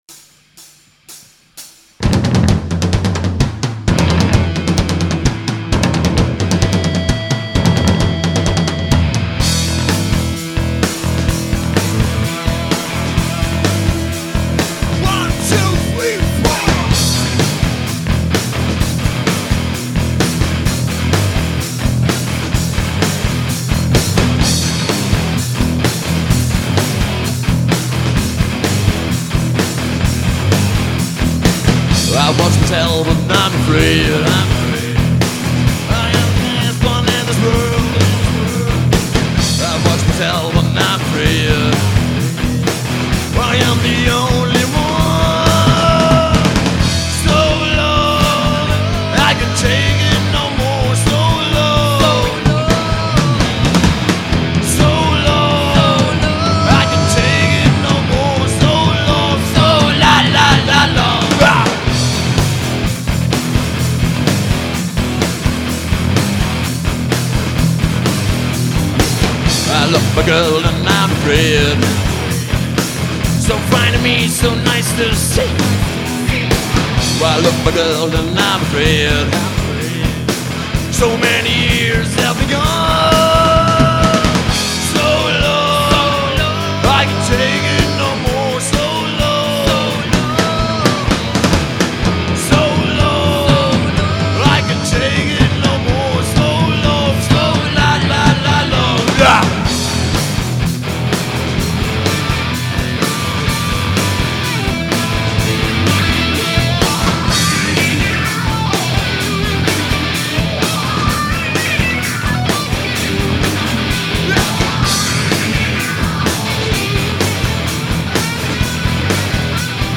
Live in Durmersheim im V8 am 16.11.2002